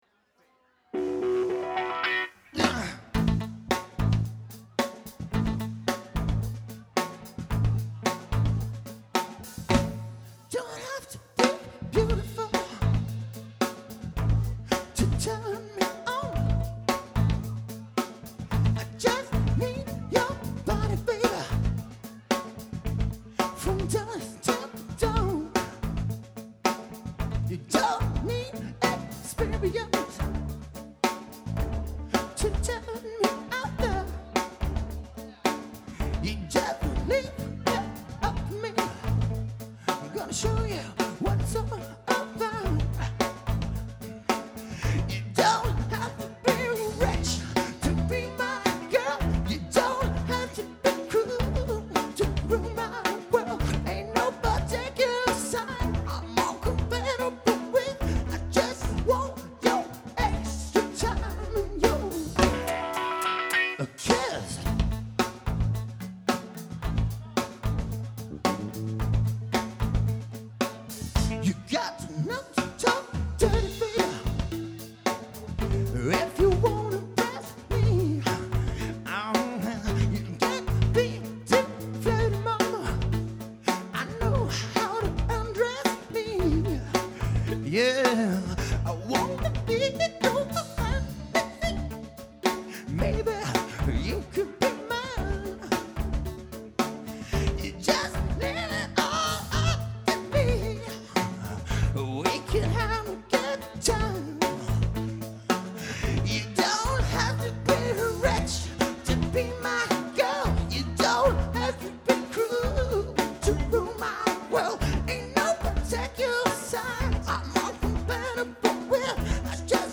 Opptak fra konsert på Vista 20.02.05